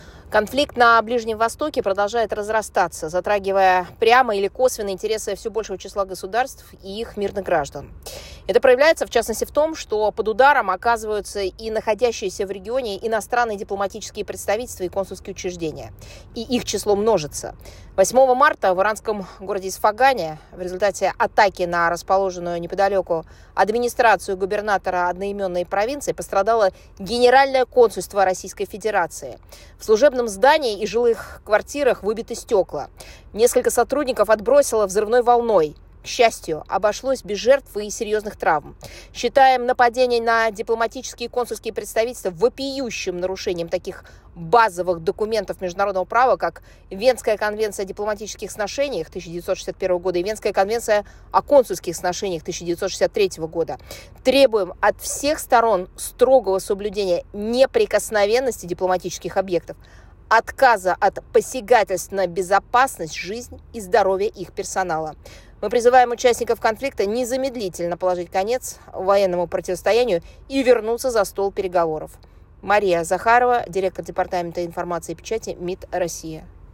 Аудиокомментарий М.В.Захаровой